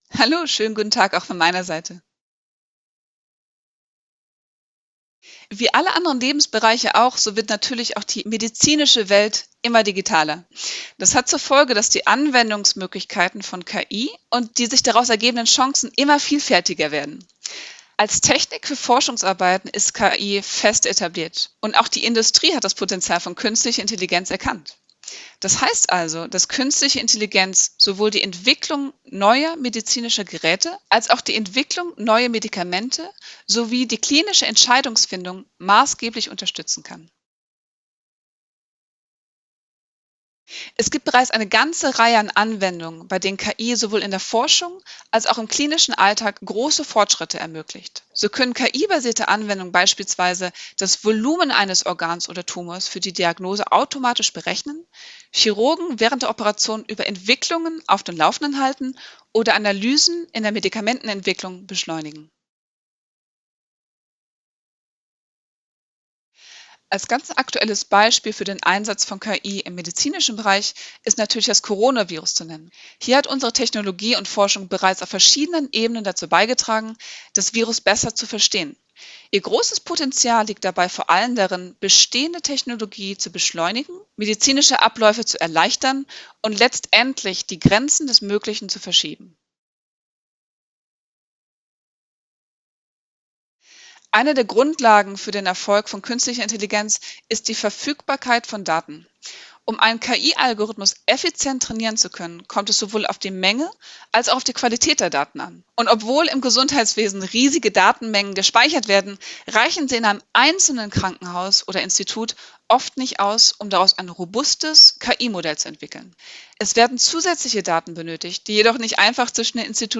Interview: Gemeinsames Lernen! KI im Gesundheitswesen.
Manuskript zum Interview